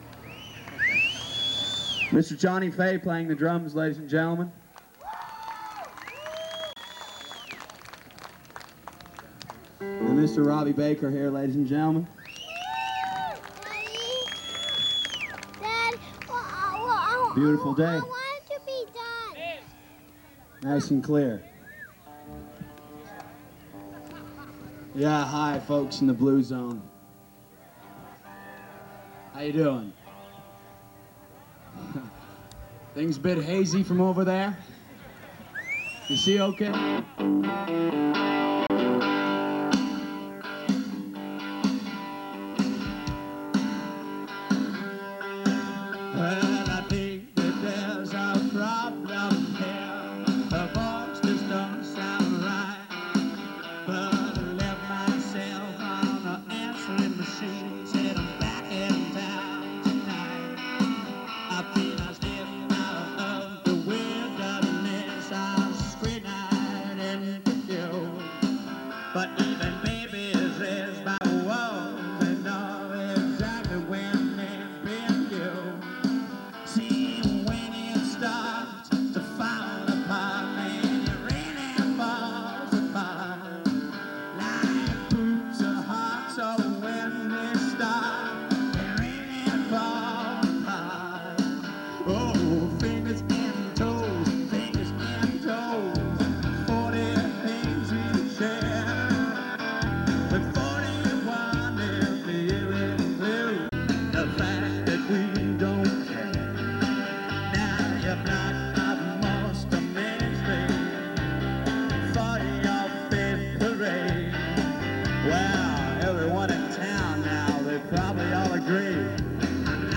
Live In 1989